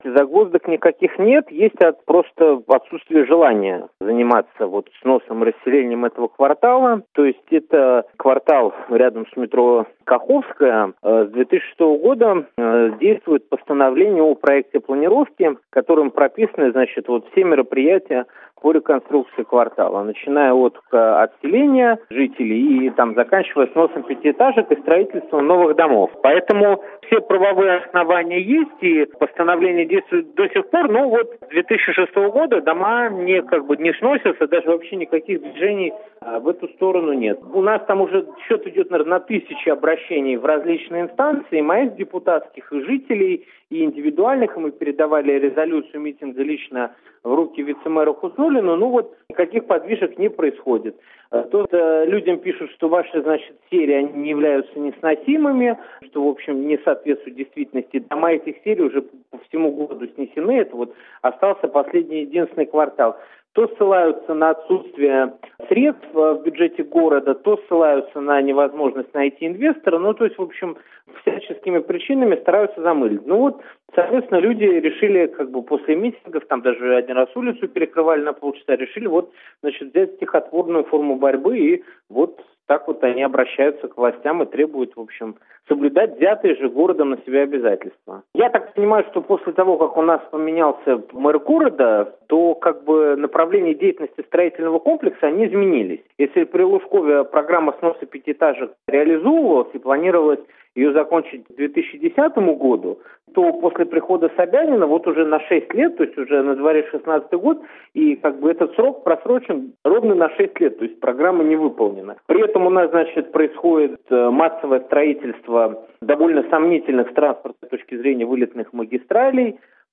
Муниципальный депутат района Зюзино Константин Янкаускас в интервью Радио Свобода заявил, что счет его депутатских обращений и заявлений от жителей в мэрию Москвы "идет уже на тысячи", однако "никаких подвижек не происходит".